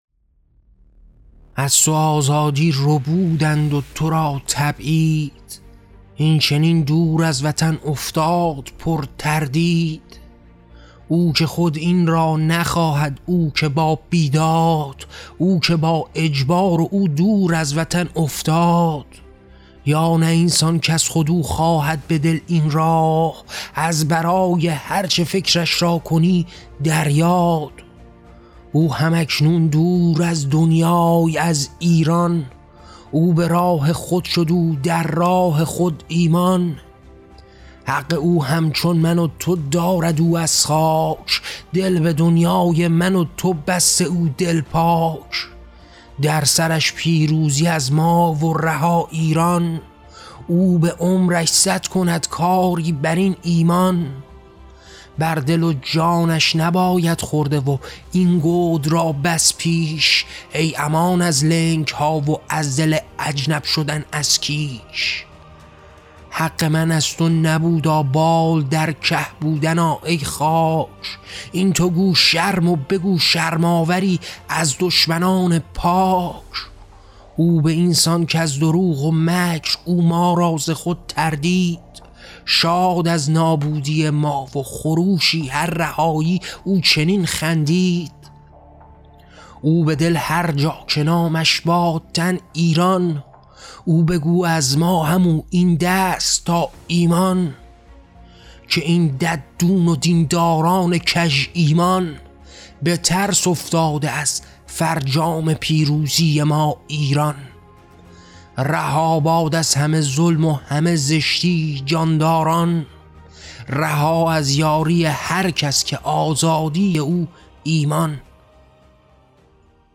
کتاب طغیان؛ شعرهای صوتی؛ تبعیدشدگان: پیوند جان‌های دور از وطن با آرمان آزادی